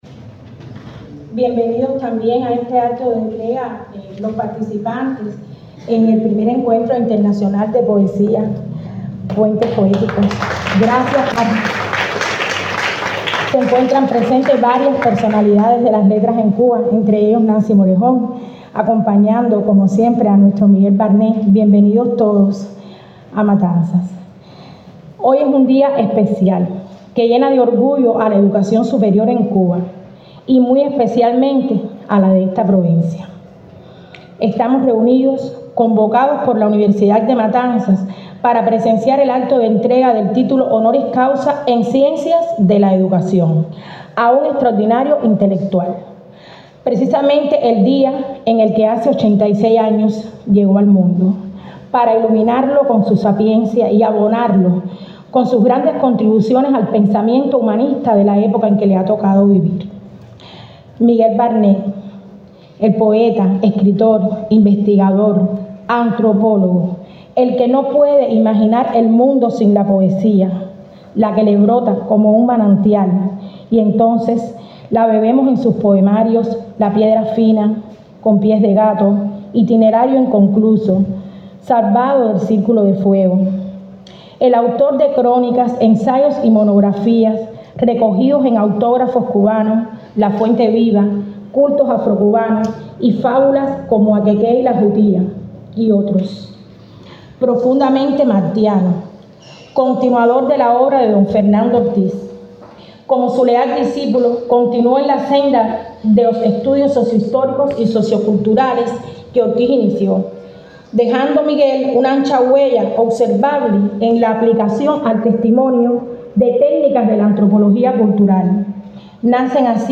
La Universidad de Matanzas reconoció al poeta, novelista, ensayista y etnólogo cubano Miguel Barnet Lanza, Presidente de Honor de la Unión de Escritores y Artistas de Cuba, con el título honorífico de Doctor Honoris Causa en Ciencias de la Educación, en la Sala White, durante las actividades del Primer Festival Internacional de Poesía Puentes Poéticos.